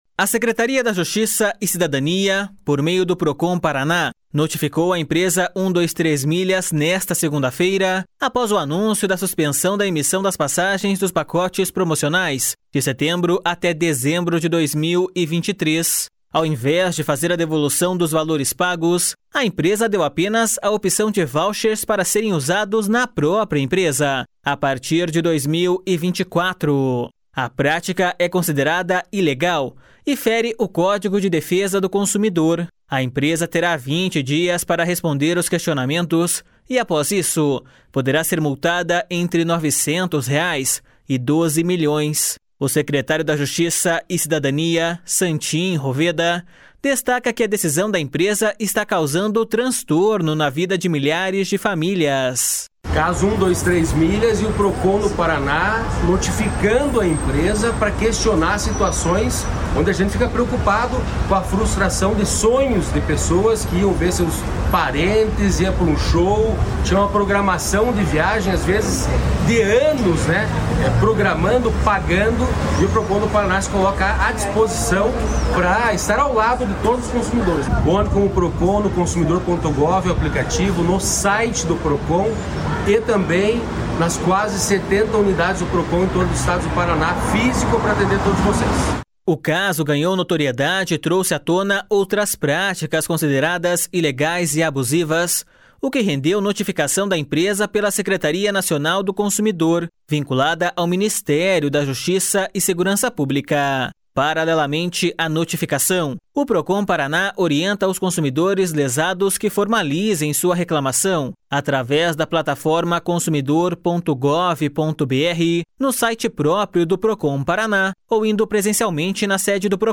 O secretário da Justiça e Cidadania, Santin Roveda, destaca que a decisão da empresa está causando transtorno na vida de milhares de famílias.// SONORA SANTIN ROVEDA.//
A coordenadora estadual do Procon-PR, Claudia Silvano, ressaltou que o órgão já está apurando a situação da empresa.// SONORA CLAUDIA SILVANO.//